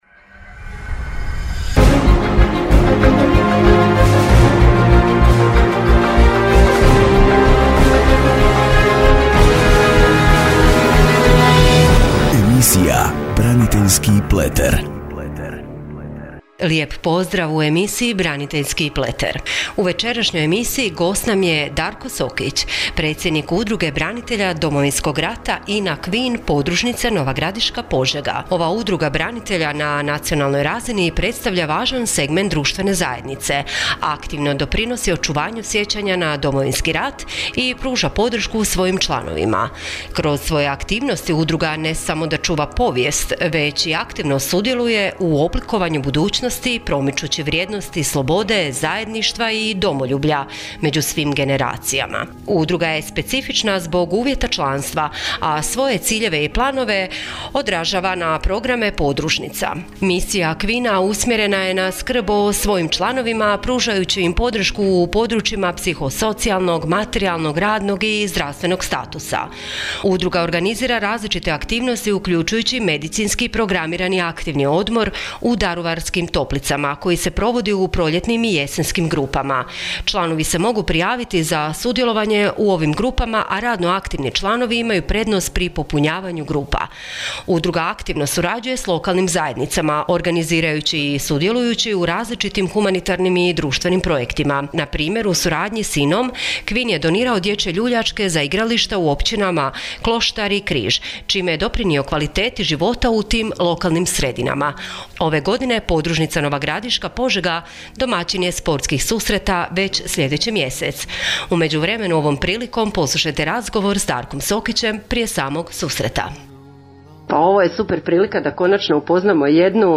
Radio Nova Gradiška (98.1Mhz)— Radijska emisija: “BRANITELJSKI PLETER”